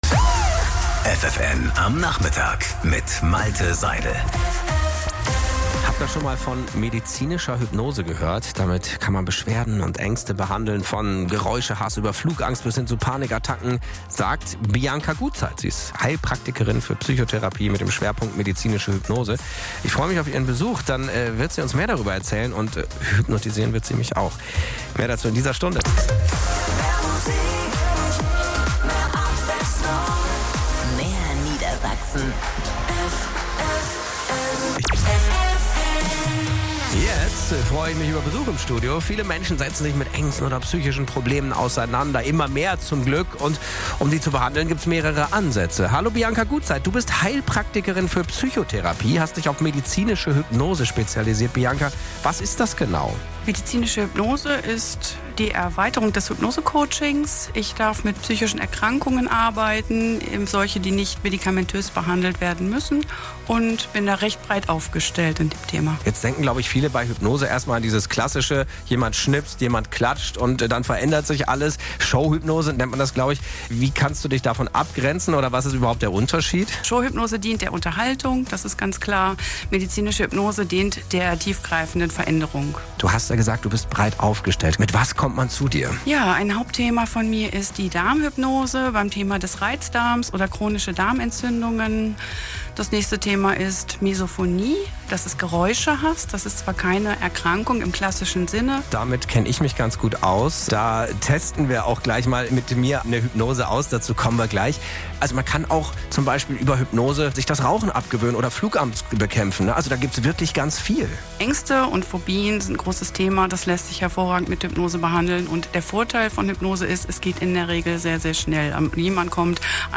Bemerkenswert ist, dass das Team von ffn total humorvoll und authentisch ist und die das Interview sehr viel Spaß gemacht hat!